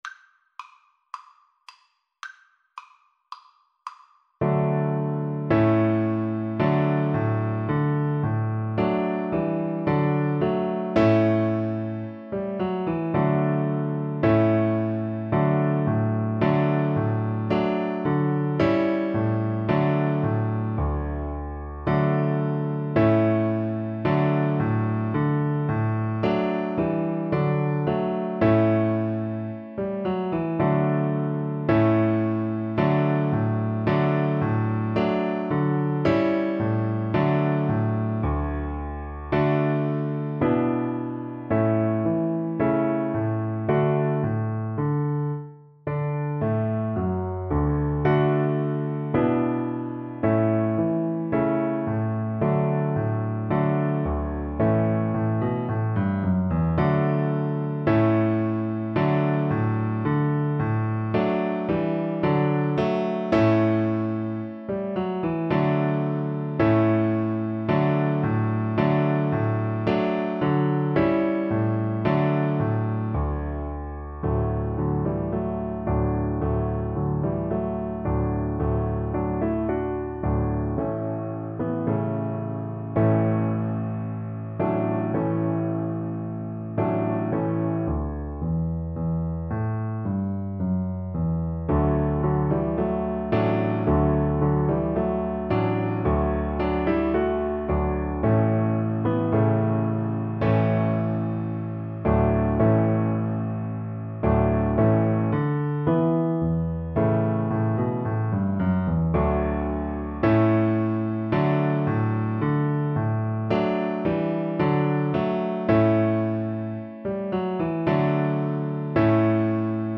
Allegro moderato =110 (View more music marked Allegro)
Classical (View more Classical Piano Duet Music)